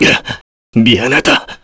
Clause-Vox_Dead_kr.wav